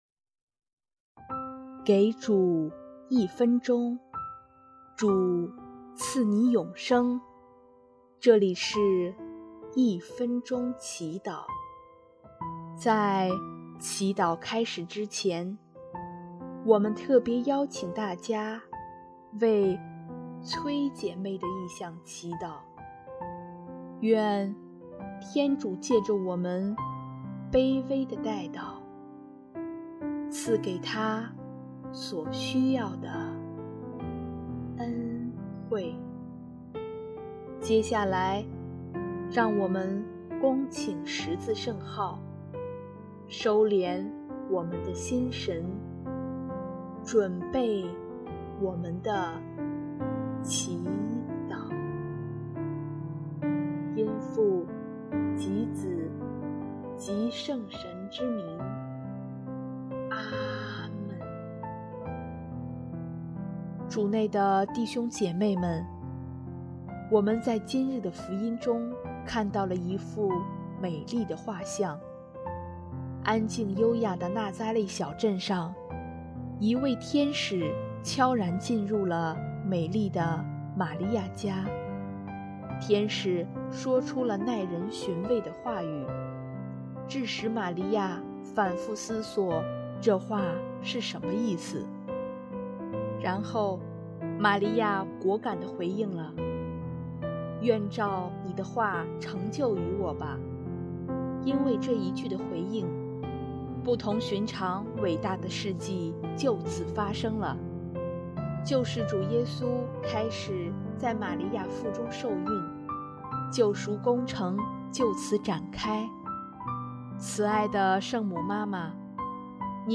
音乐： 第二届华语圣歌大赛参赛歌曲《我爱天主》